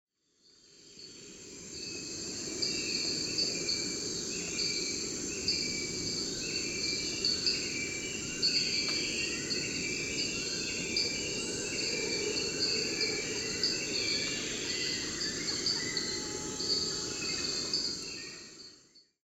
Paisaje sonoro al amanecer en Gandoca.
4_paisaje_manana_gandoca_sebr.mp3